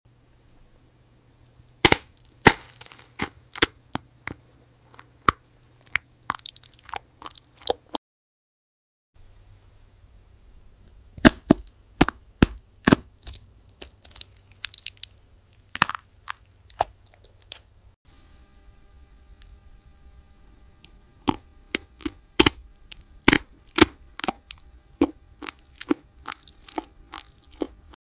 🌈 Frozen Rainbow Jelly Cube sound effects free download
🌈 Frozen Rainbow Jelly Cube Crunch | Satisfying ASMR Bite 🎧 A rainbow jelly cube, frozen solid and frosted with ice crystals. Watch as it’s held delicately between thumb and forefinger, then bitten into with a crisp icy snap, a jiggly wobble, and finally a squishy chew. Colorful, crunchy, and insanely satisfying ASMR.